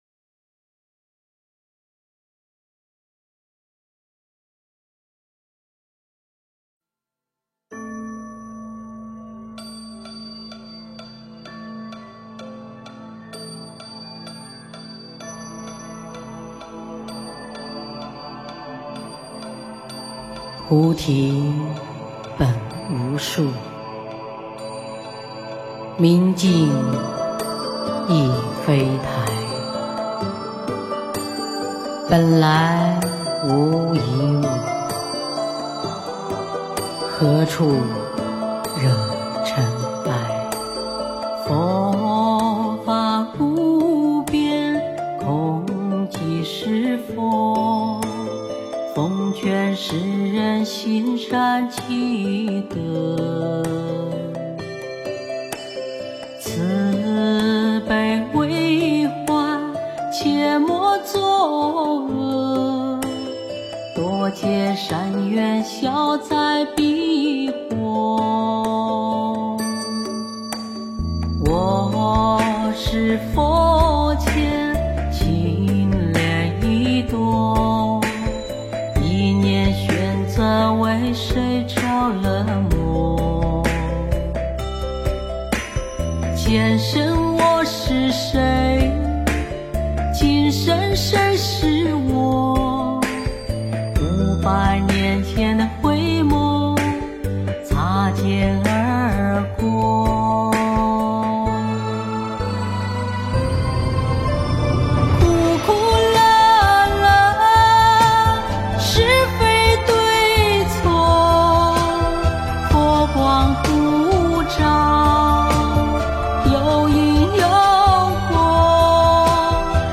佛音 诵经 佛教音乐 返回列表 上一篇： 百善孝为先 下一篇： 内心深处、苦乐源头 相关文章 峨眉金顶(纯音乐